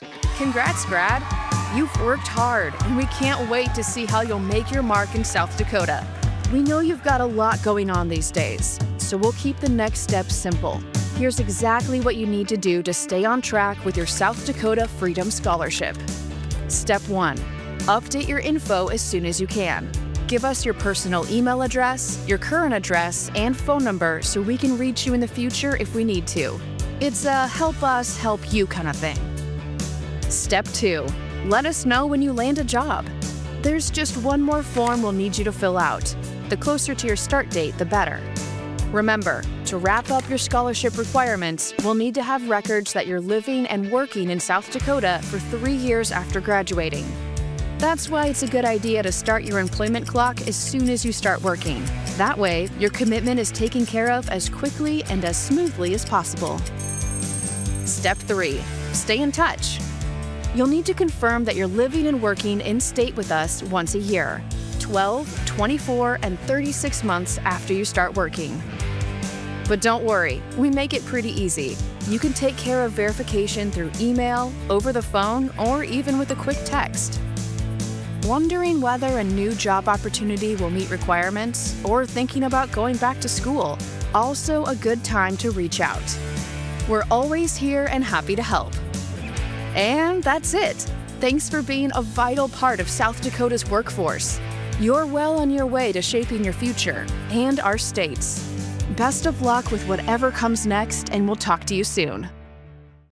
Natural, Urbana, Cálida
Explicador